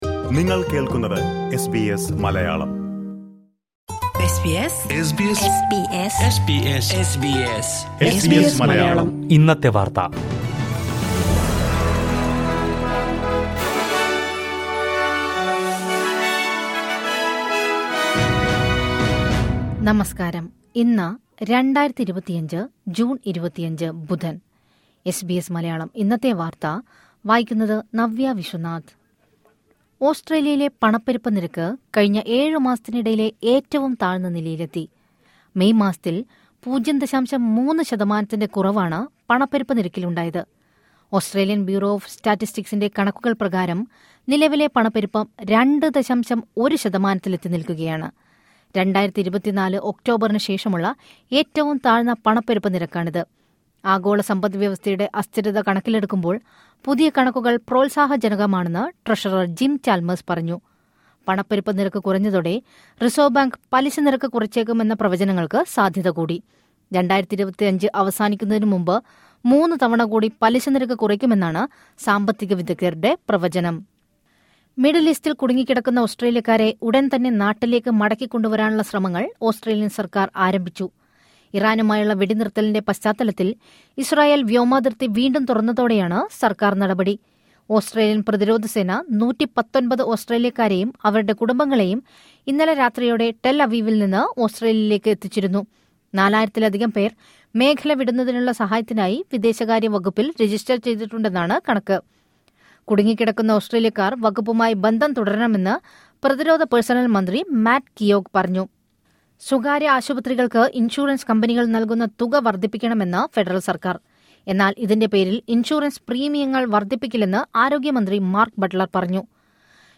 2025 ജൂൺ 25ലെ ഓസ്‌ട്രേലിയയിലെ ഏറ്റവും പ്രധാന വാര്‍ത്തകള്‍ കേള്‍ക്കാം...